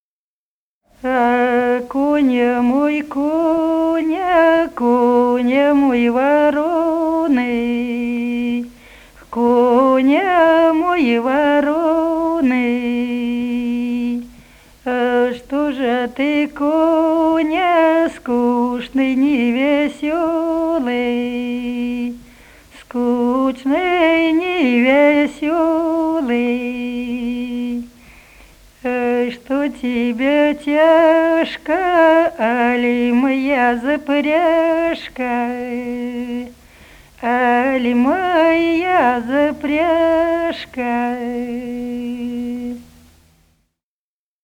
Народные песни Смоленской области
«А коня мой, коня» (лирическая мужская).